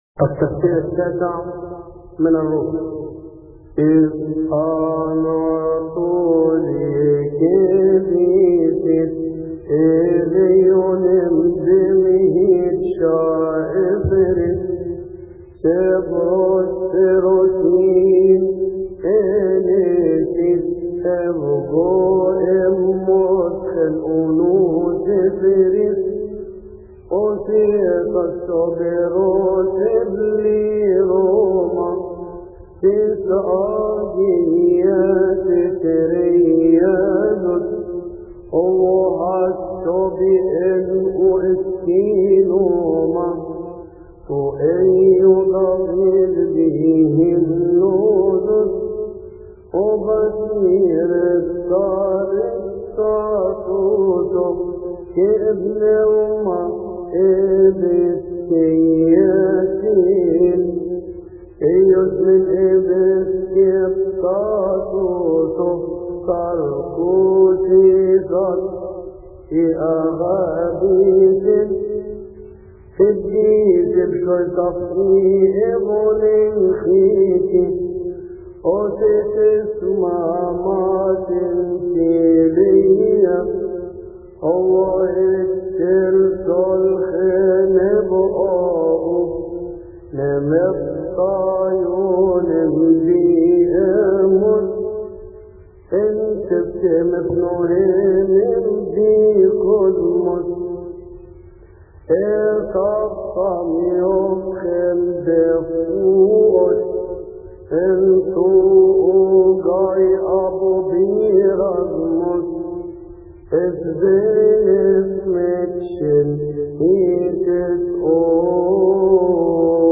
المرتل
التفسير التاسع من الرومي لثيؤطوكية السبت يصلي في تسبحة عشية أحاد شهر كيهك